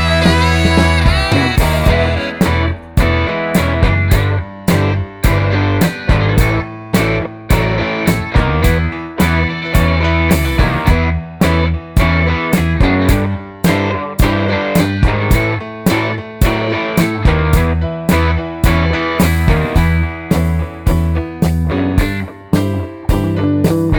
No Sax Solo Soundtracks 3:20 Buy £1.50